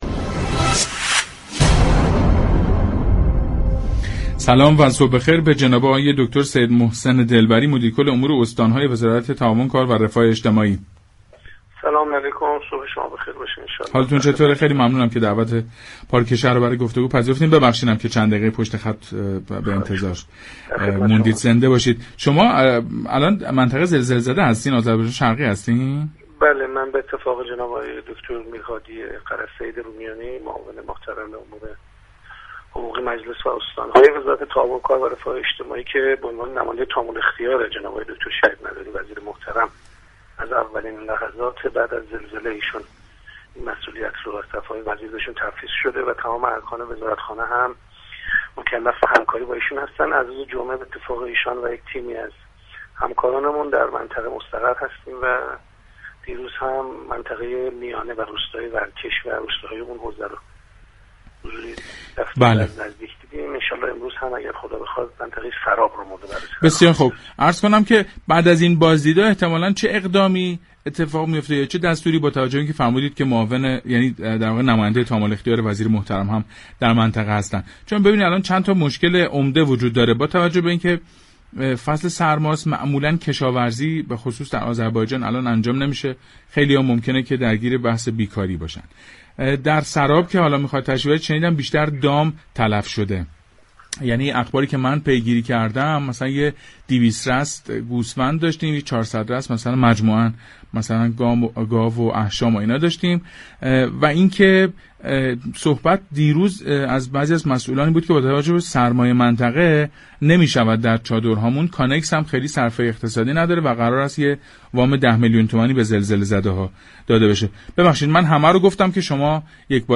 سید محسن دلبری مدیركل دفتر امور استان‌های وزارت تعاون، كار و رفاه اجتماعی در گفتگو با پارك شهر درباره اقدام‌های این وزارتخانه در مناطق زلزله‌زده آذربایجان سخن گفت.